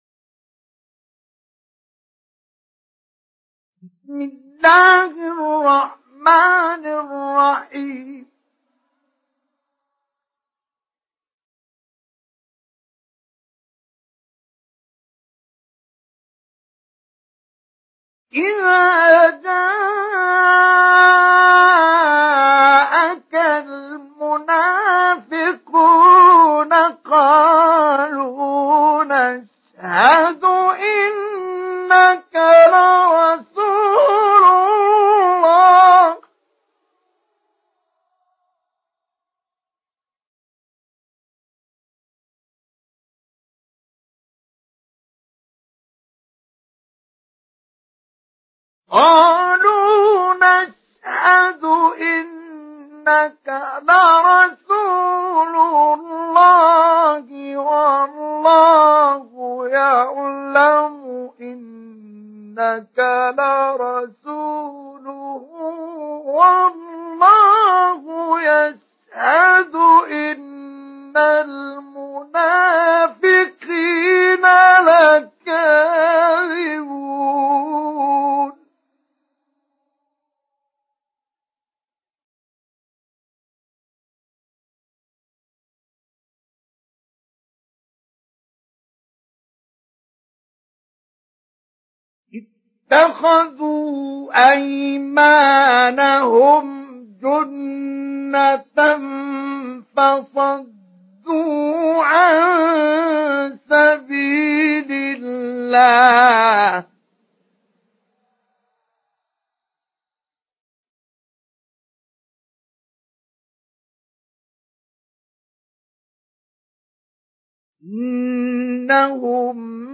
Surah Al-Munafiqun MP3 in the Voice of Mustafa Ismail Mujawwad in Hafs Narration
Surah Al-Munafiqun MP3 by Mustafa Ismail Mujawwad in Hafs An Asim narration.